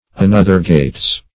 Search Result for " another-gates" : The Collaborative International Dictionary of English v.0.48: Another-gates \An*oth"er-gates`\, a. [Another + gate, or gait, way.